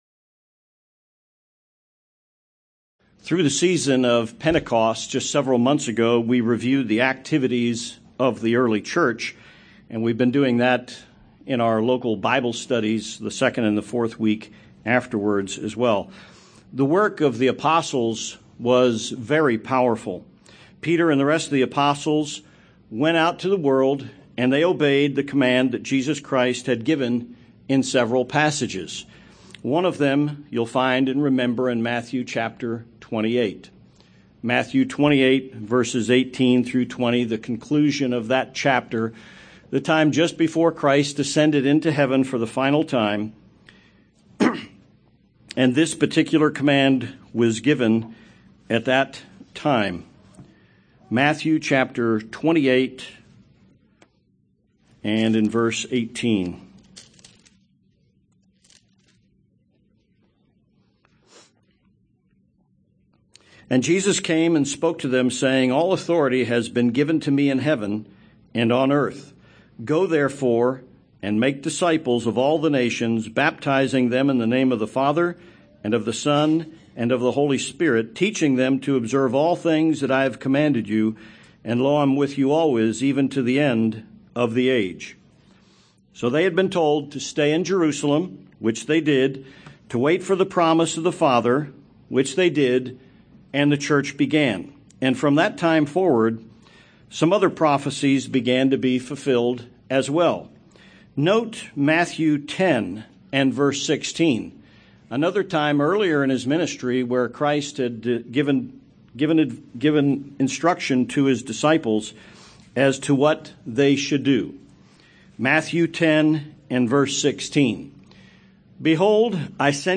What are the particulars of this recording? Given in El Paso, TX